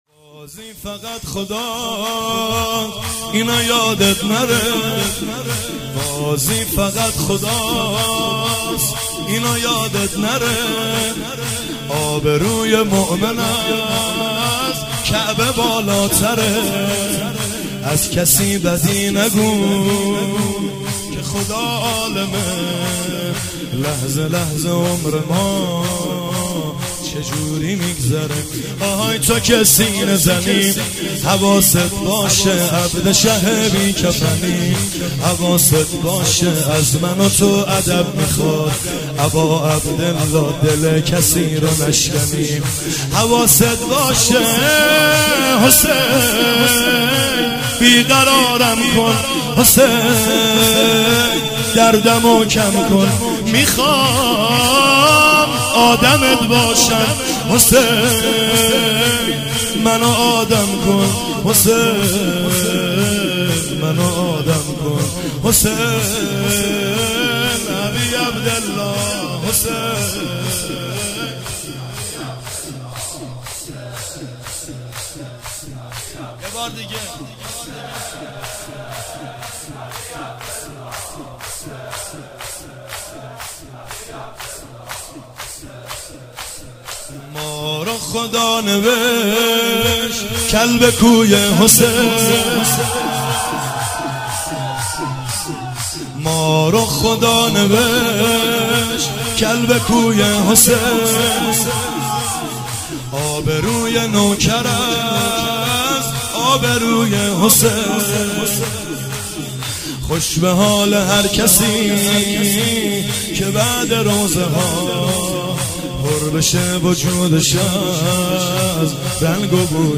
دانلود جدیدترین و گلچین بهترین مداحی های محرم